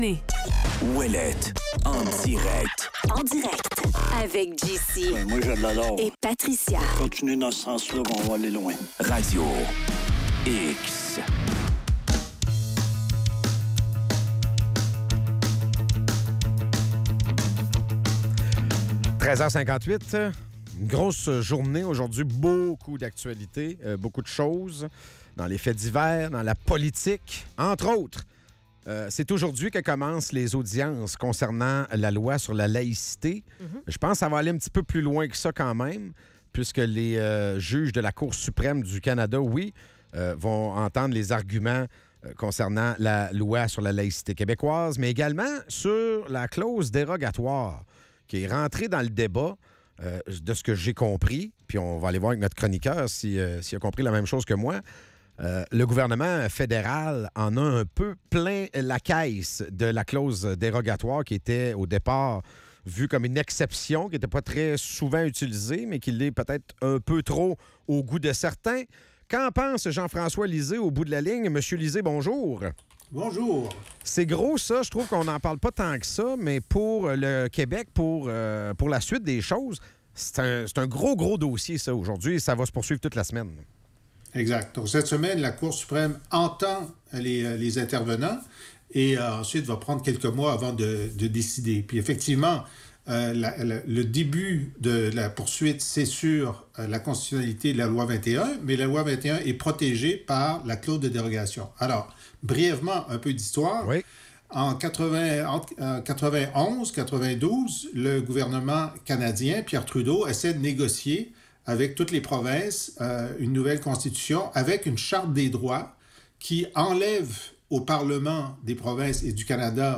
En chronique, Jean-François Lisée.